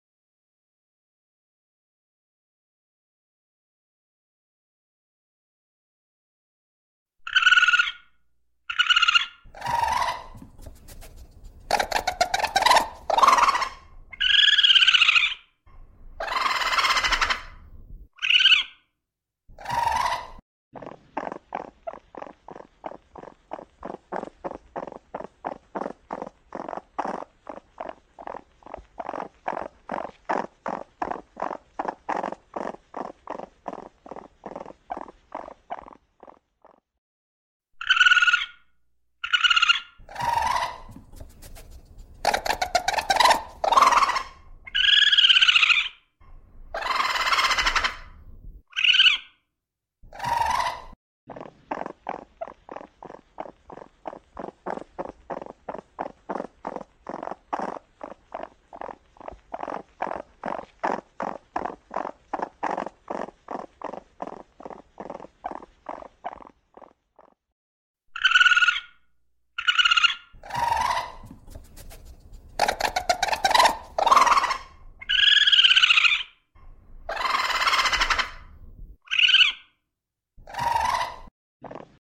На этой странице собрана коллекция звуков, которые издают еноты.
Звук проказливого енота